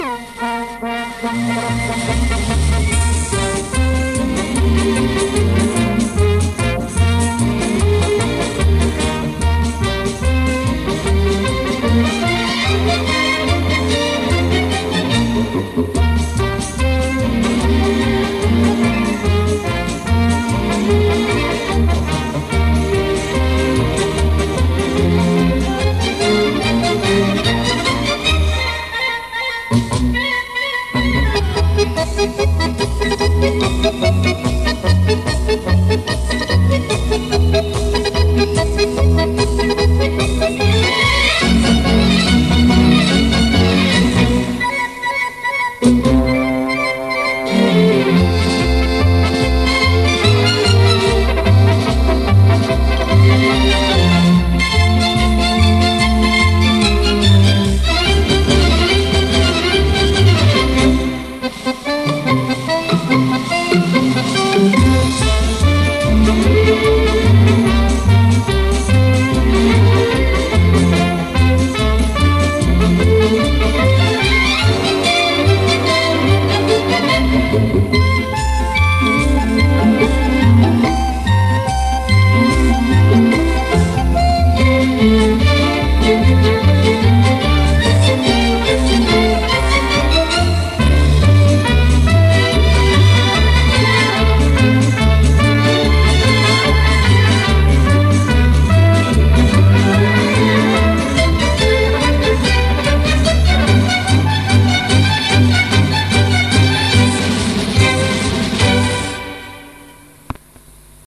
Полька.mp3